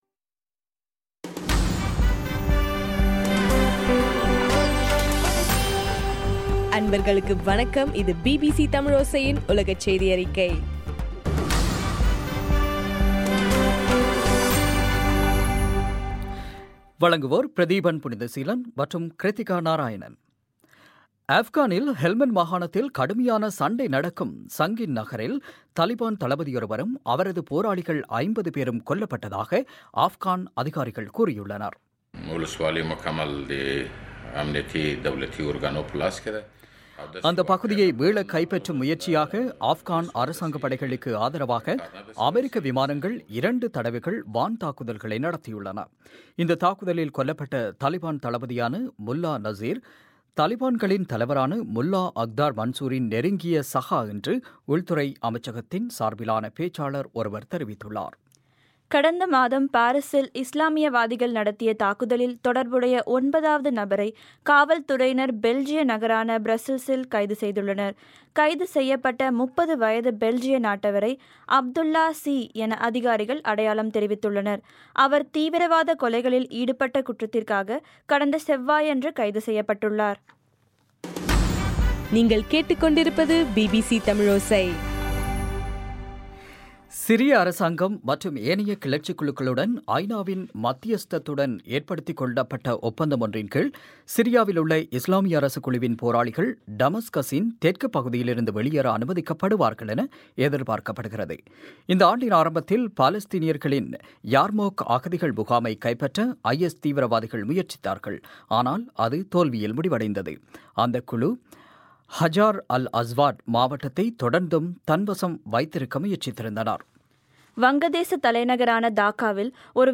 டிசம்பர் 24, 2015 பிபிசி தமிழோசையின் உலகச் செய்திகள்